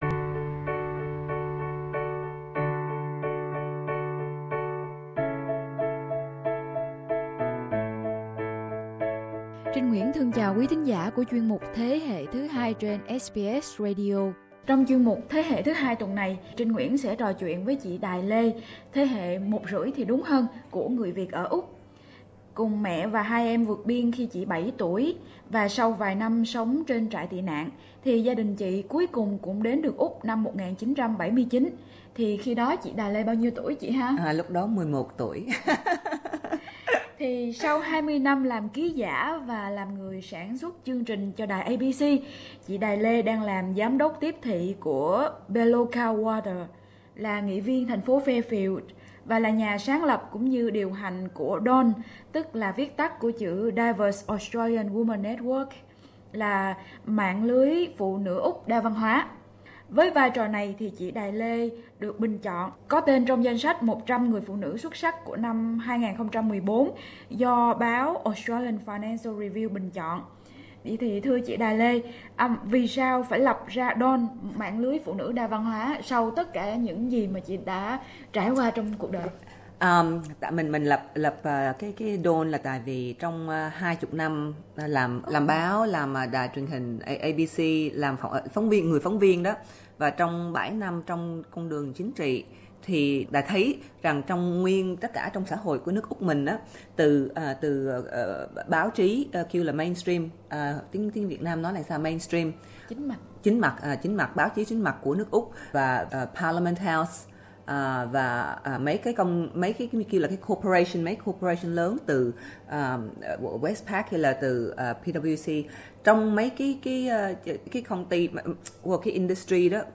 Trò chuyện với Đài Lê, nhà sáng lập và điều hành Mạng Lưới Phụ Nữ Úc Đa Sắc Tộc (DAWN) về vai trò lãnh đạo của phụ nữ đến từ các nền văn hóa khác nhau trong xã hội nước Úc.